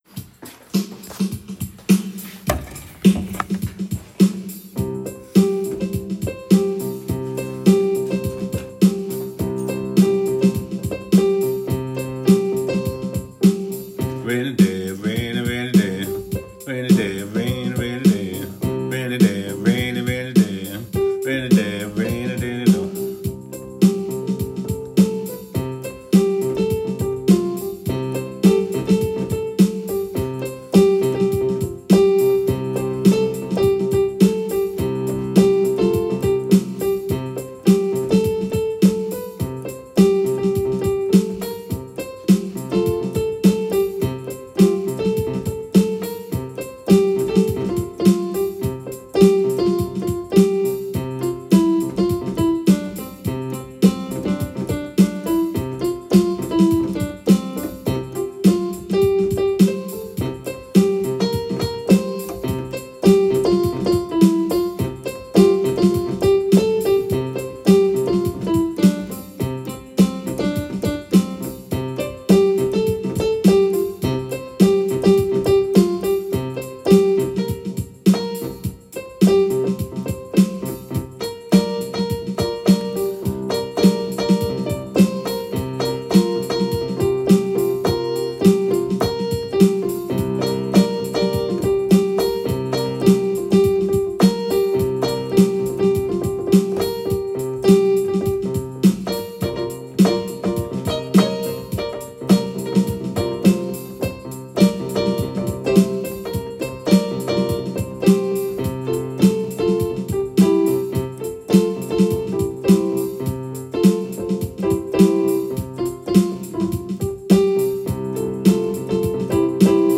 View Open Audio File: First Rehearsal